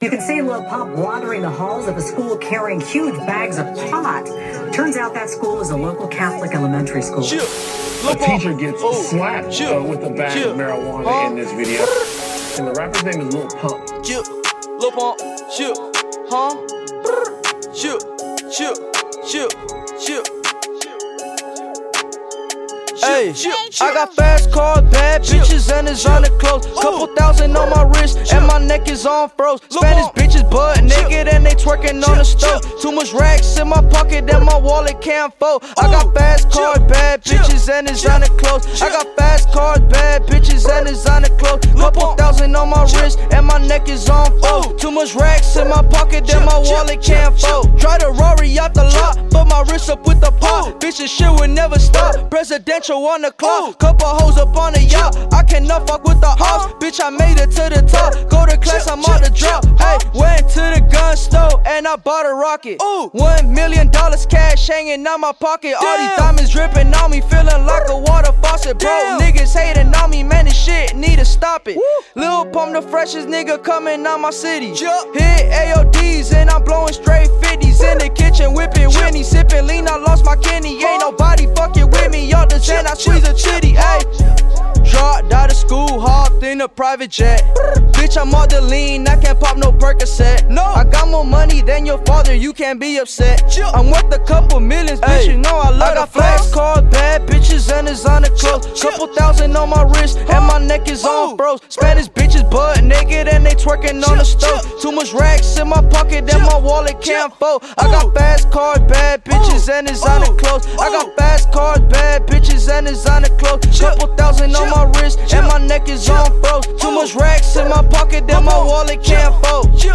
Жанр: Популярная музыка